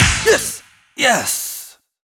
goodClap4.wav